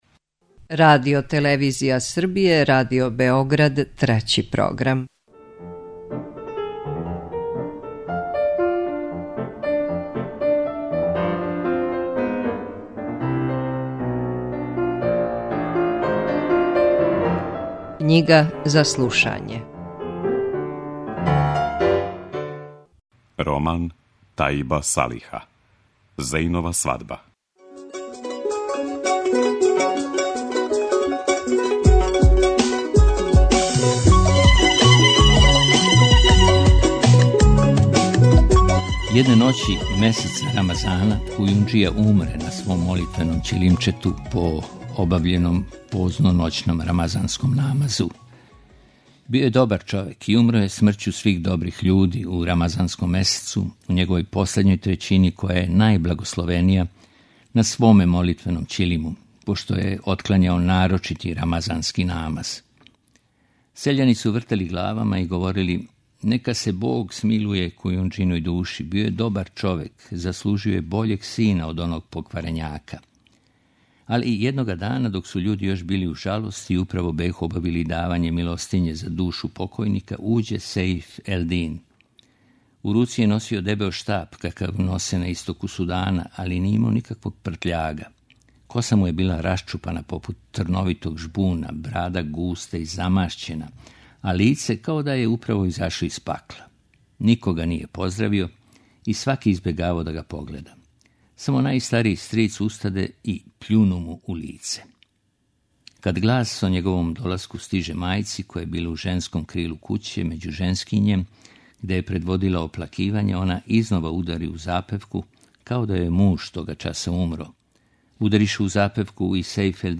У циклусу КЊИГА ЗА СЛУШАЊЕ, можете пратити шести наставак романа 'Зејнова свадба' чији је аутор Тајиб Салих.
Књига за слушање